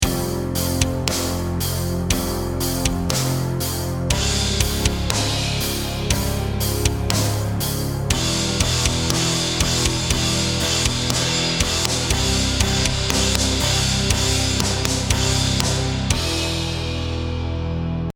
The first 4 bars of the attached tab below show a riff in F minor. The theme is stated, using the repeating "G" pedal tone for context.
The next 4 bars modulate to the key of E minor.